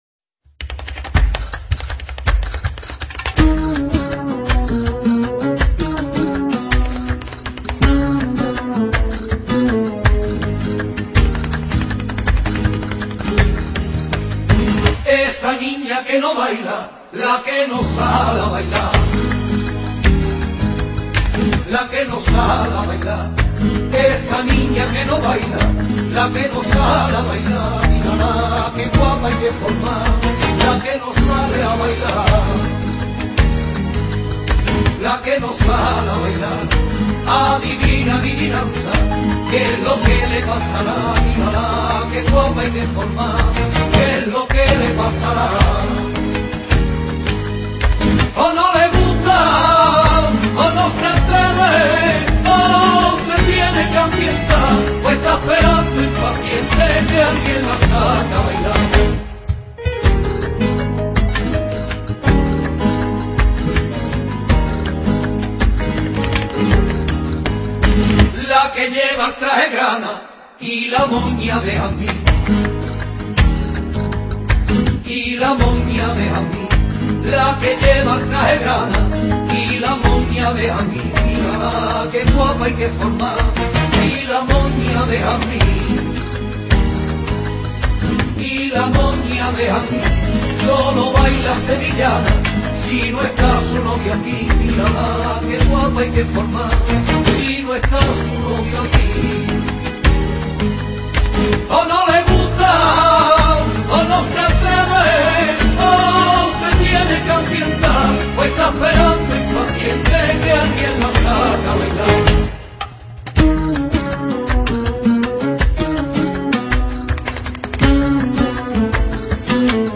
una entrega semanal de sevillanas para la historia.